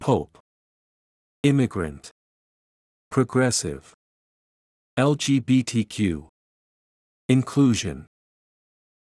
pope /poʊp/（名）ローマ教皇、法王
immigrant /ˈɪmɪɡrənt/（名）移民（他国から移住してきた人）
progressive /prəˈɡrɛsɪv/（形）進歩的な、革新的な
inclusion /ɪnˈkluːʒən/（名）包含、受け入れ、参加の機会を与えること